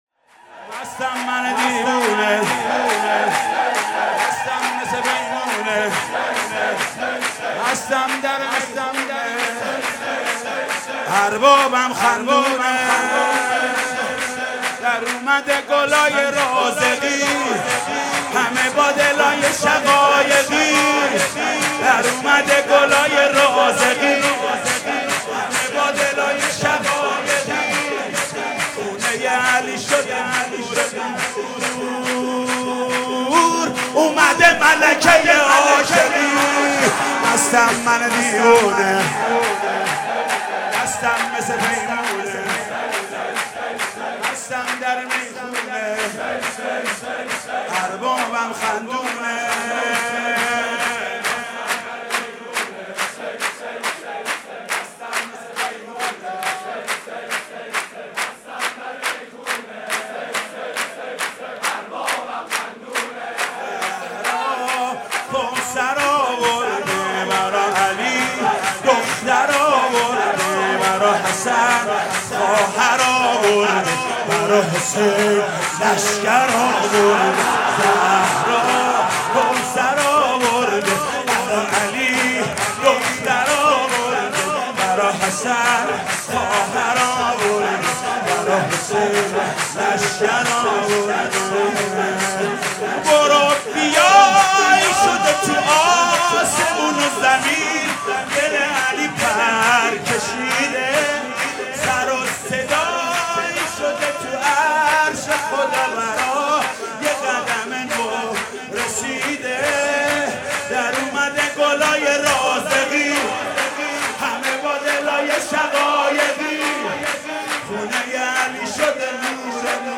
ولادت حضرت زینب (سرود)